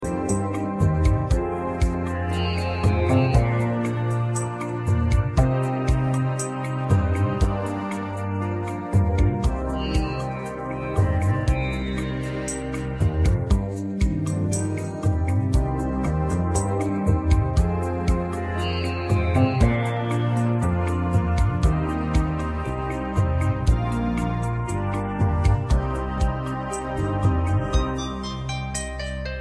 ویژگی‌های این موزیک بی‌کلام:
🎵 کیفیت صدای عالی و استودیویی
🎧 بدون افت کیفیت و نویز
پاپ